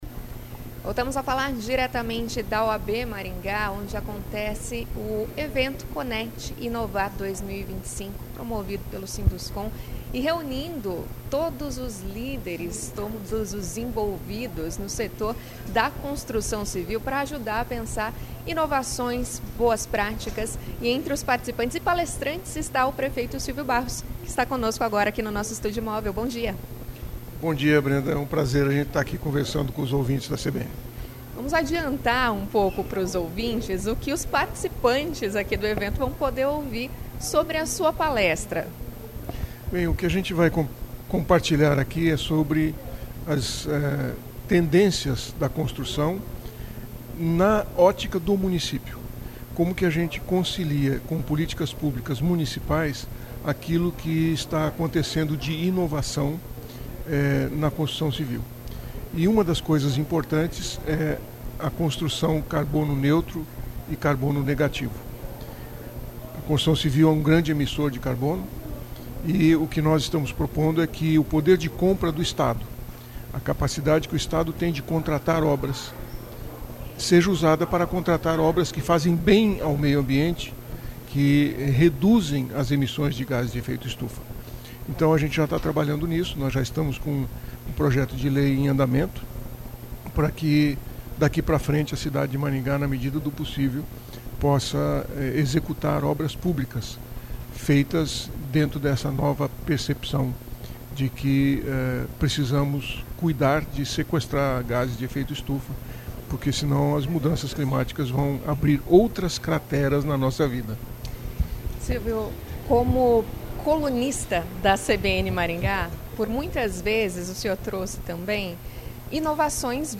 Silvio Barros, prefeito de Maringá.
A entrevista foi realizada no Estúdio Móvel da CBN, instalado na sede da OAB Maringá, de onde ocorre a edição do Conecti, que antecede a cerimônia de entrega do Prêmio Sinduscon 2025.